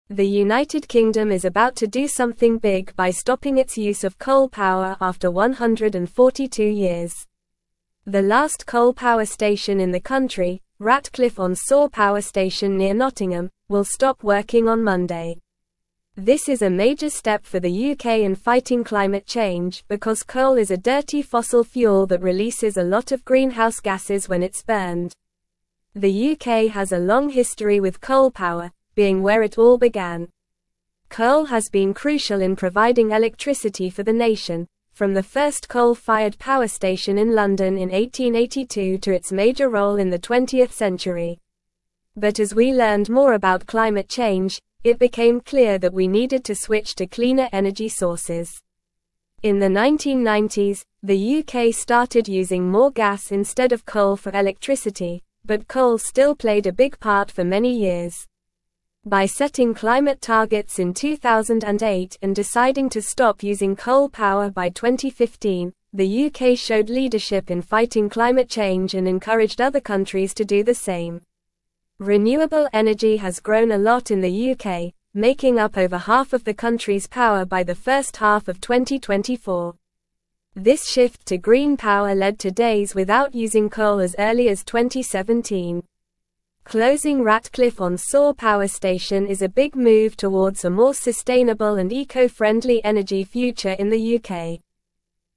Normal
English-Newsroom-Upper-Intermediate-NORMAL-Reading-UK-Ends-142-Year-Coal-Reliance-Shifts-to-Renewables.mp3